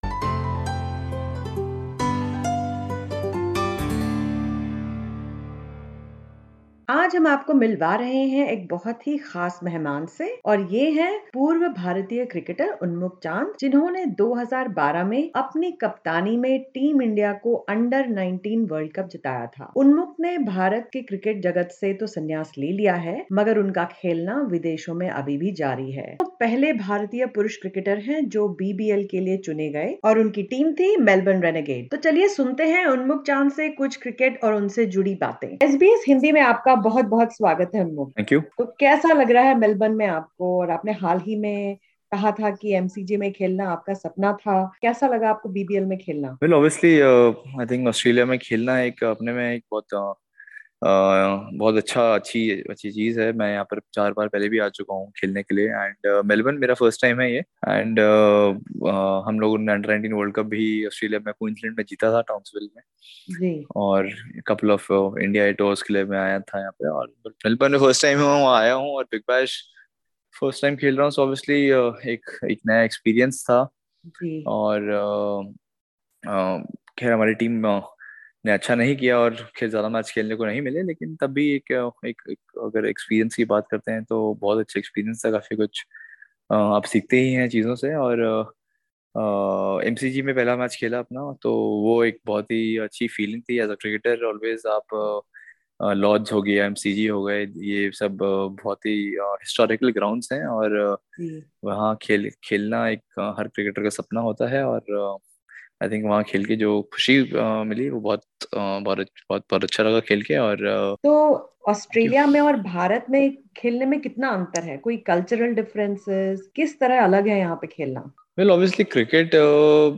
Unmukt Chand, India’s former Under-19 World Cup-winning captain, has made history this year by becoming the first male Indian cricketer to play in the Big Bash League (BBL) in Australia. In an exclusive interview with SBS Hindi, Mr Chand looks back at his decision to quit Indian cricket and shares his love for playing the game in Australia.